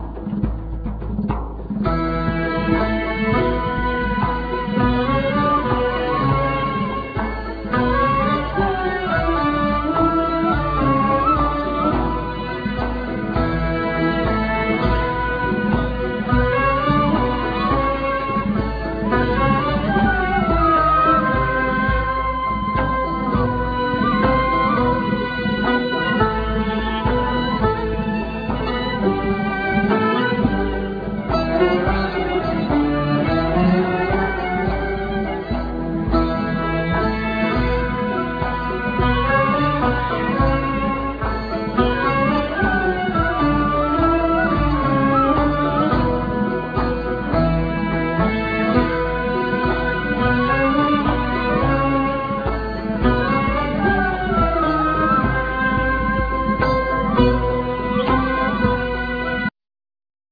Zarb,Bendir,Udu
Zarb,Daff
Ney
Cello
Lyra,Saz,Rabab,Kemanche,Oud,Laouto